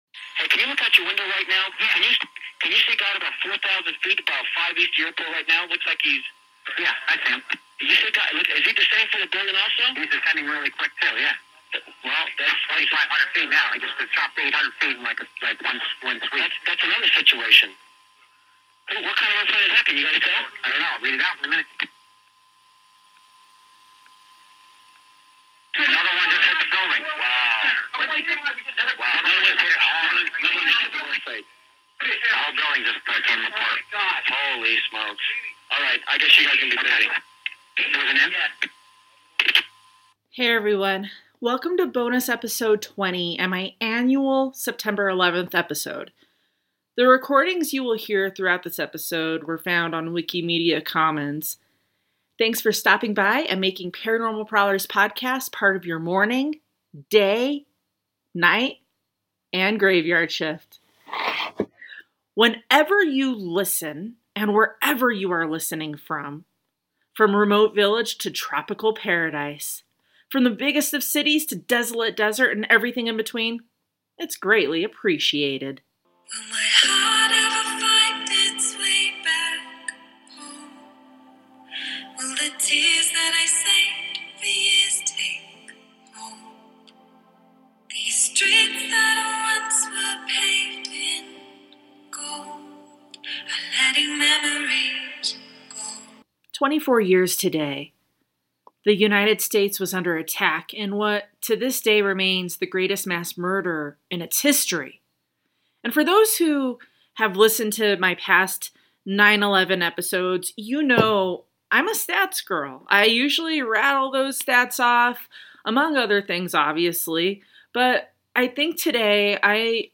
NEVER FORGET CREDITS & LINKS AUDIO : WikiMedia Commons New York Control tower to NY TRACON.ogg Two New York air traffic controllers communicate the positioning of United Airlines Flight 175 live and see the collision of the flight into the South Tower of the WTC.
Radio communication between air traffic controllers from Boston and NORAD.